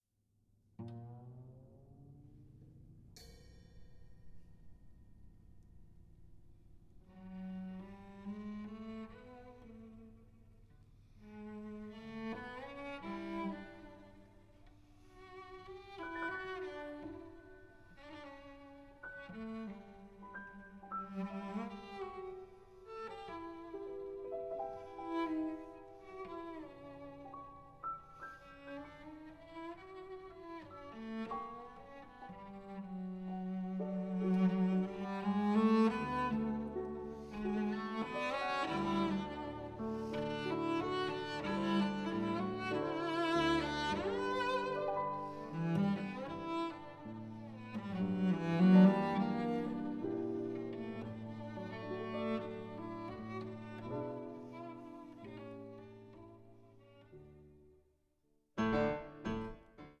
Cello
Klavier
Cello und Klavier